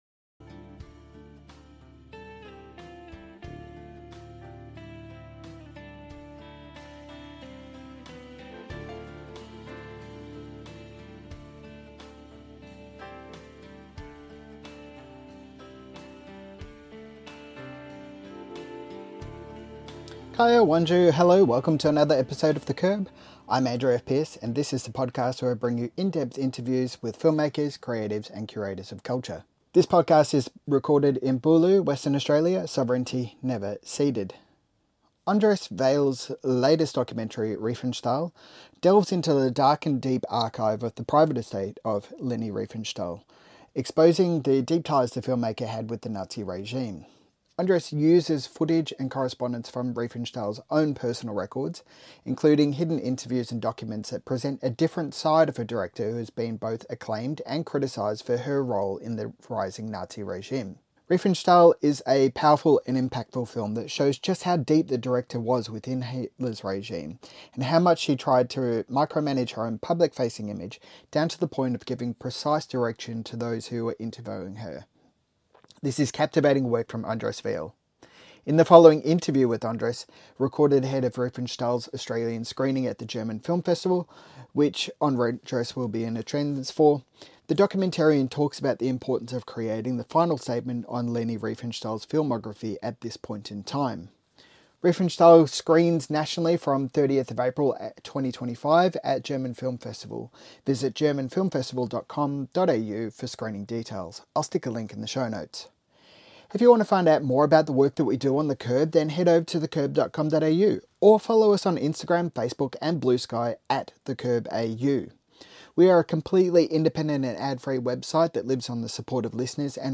In the above interview with Andres Veiel, recorded ahead of Riefenstahl 's Australian screenings at the German Film Festival , which Andres will be in attendance for, the documentarian talks about the importance of creating the final statement on Leni Riefenstahl's filmography at this point in time.